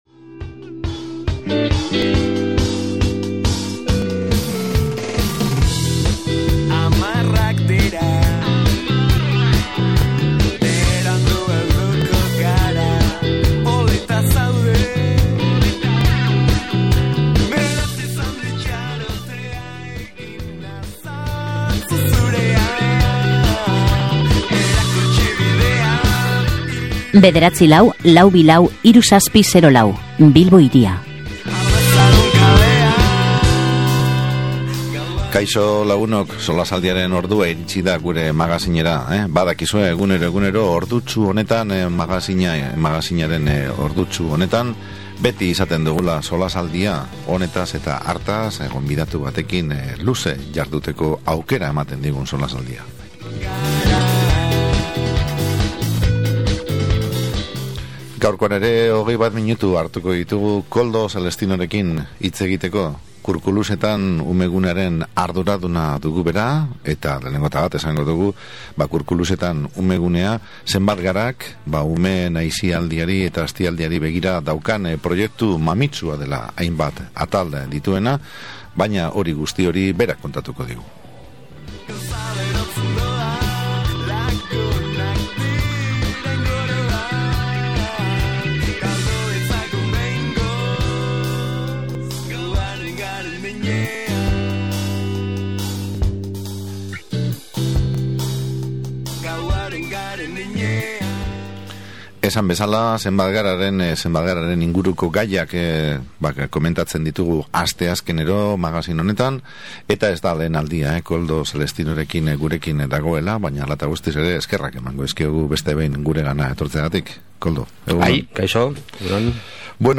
SOLASALDIA: Zenbat Gara elkartearen Umeguneaz